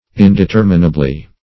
indeterminably - definition of indeterminably - synonyms, pronunciation, spelling from Free Dictionary
In`de*ter"mi*na*bly, adv.